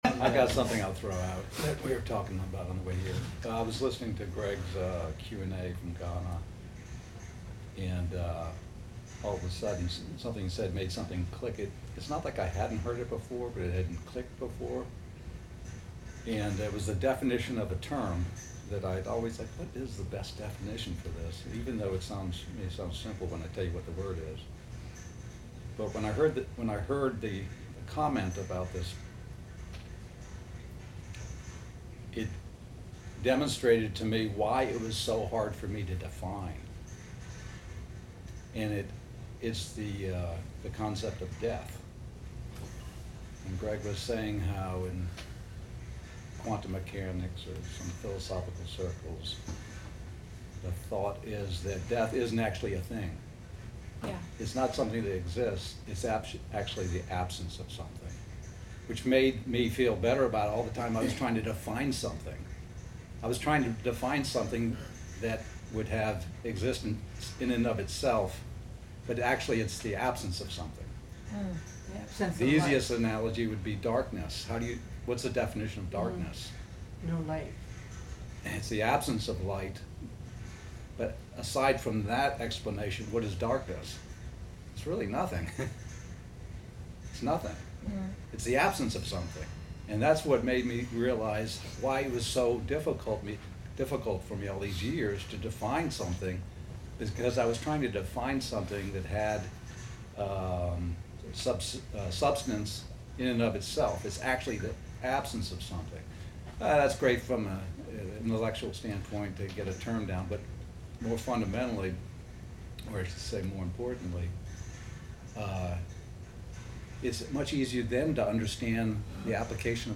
Sunday Bible Study: It's Christ Who Completes You - Gospel Revolution Church